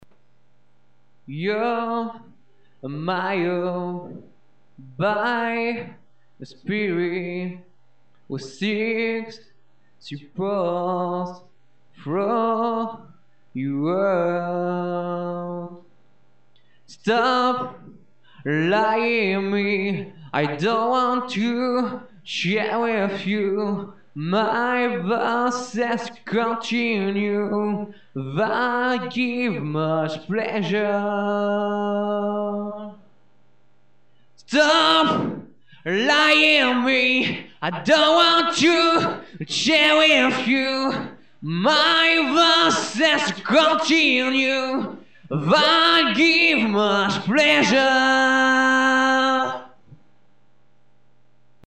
Ca faisait un p'tit moment, et là je viens d'avoir un cable pour m'enregistrer sur mon pc donc j'en ai profiter pour m'amuser, j'ai enregistrer 2/3 trucs à l'arrache avec juste la voix, sans parole, c'est du "yaourt" donc , 2 p'tite compos et un trip (enfin le tout est un trip aussi
(çà fait zarb sans musique derrière,lol)
moi je trouve ça très naturel, ya de l'assurance dans la voix,